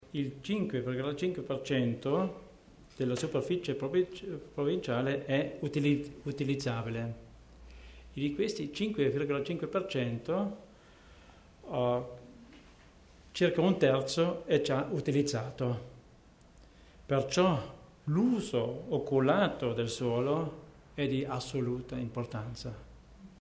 L'Assessore Theiner spiega le novità in tema di legge urbanistica